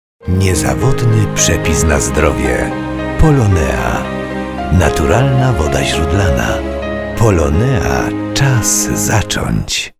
reklama radiowa #1